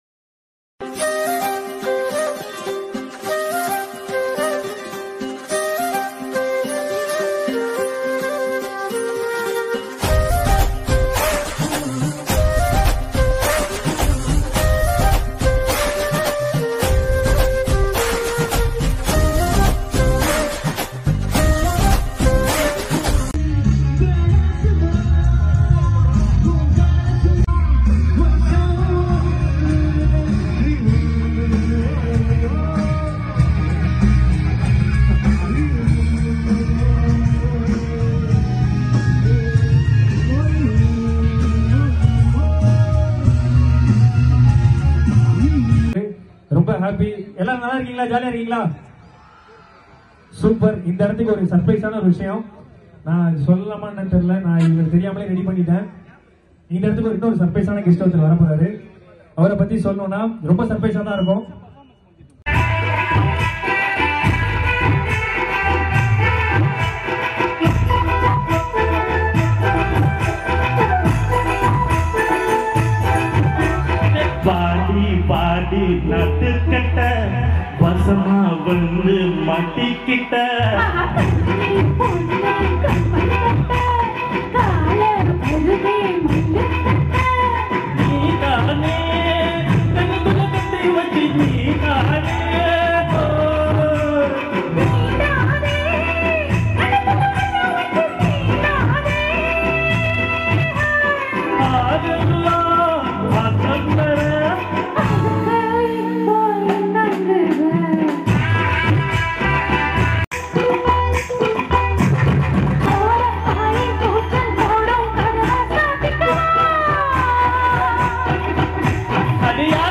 LIVE PERFORMANCE
IN KUMBAKONAM, THANJAVUR, TAMIL NADU